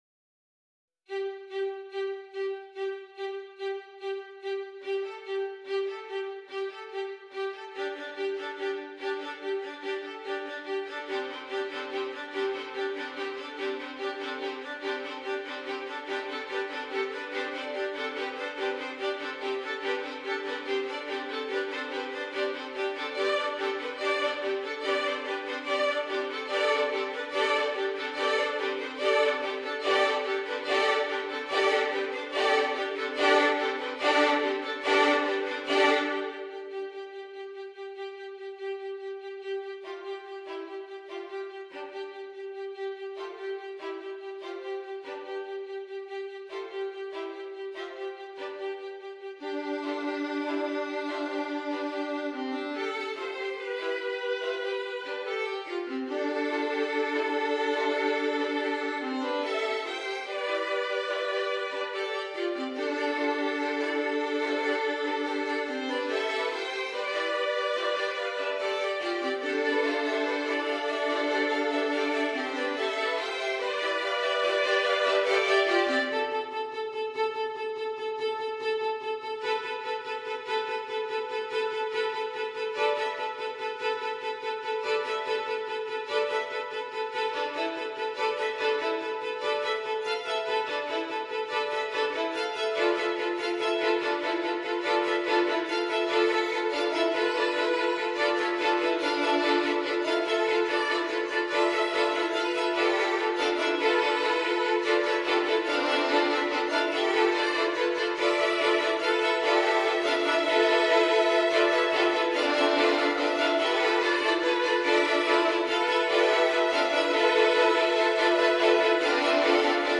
Voicing: Flex String Orchestra